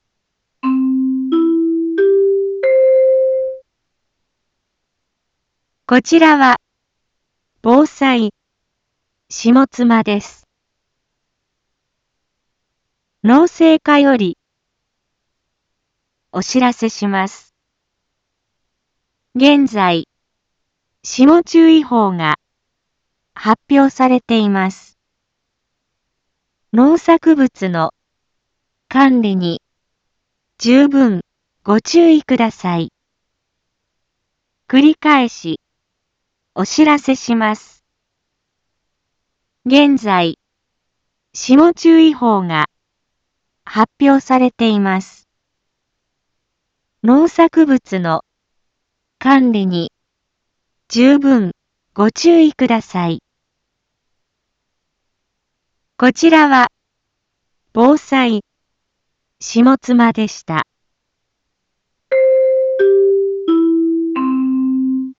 Back Home 一般放送情報 音声放送 再生 一般放送情報 登録日時：2021-04-26 18:01:08 タイトル：霜注意報 インフォメーション：こちらは防災下妻です。